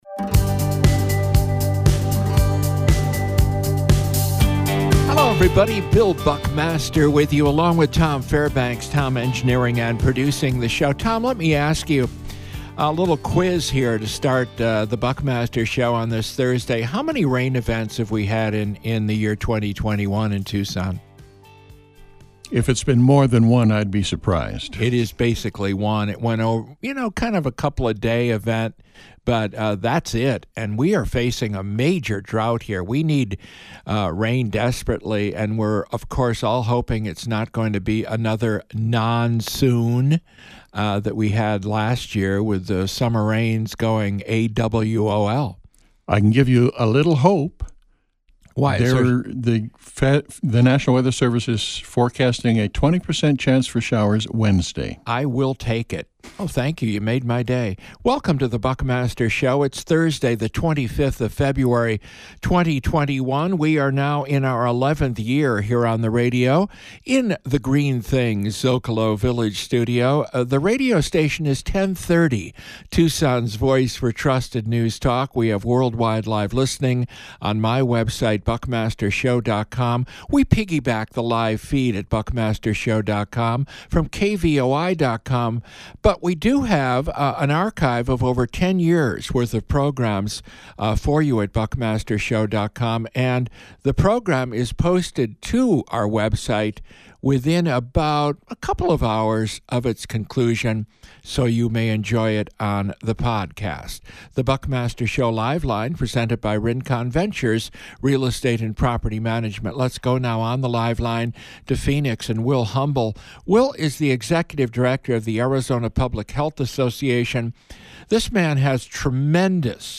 A newsmaker interview with Will Humble, former director of the Arizona Dept. of Health. Also Sharon Bronson, chairwoman of the Pima County Board of Supervisors.